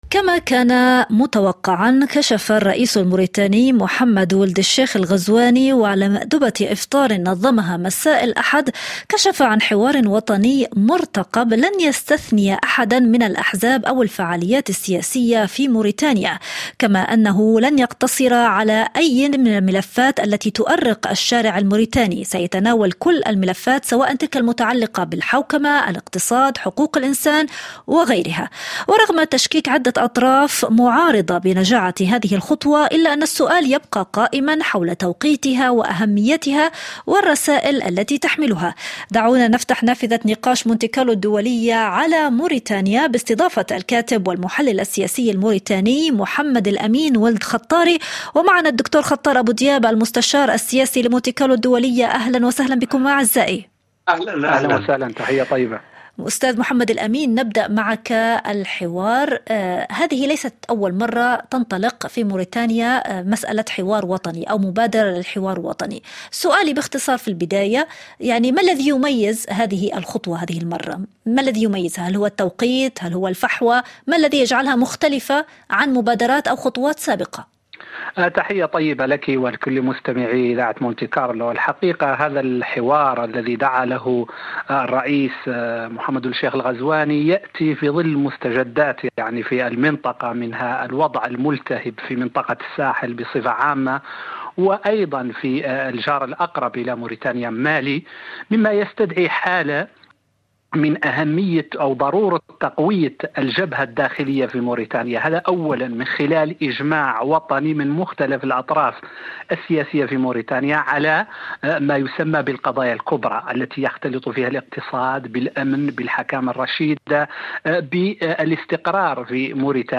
فقرة إخبارية تحليلية تأتيكم في موعدين يوميين نناقش فيهما أحداث الساعة برفقة صناع الحدث والمحللين السياسيين في "مونت كارلو الدولية".